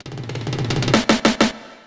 Fill 128 BPM (23).wav